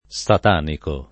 [ S at # niko ]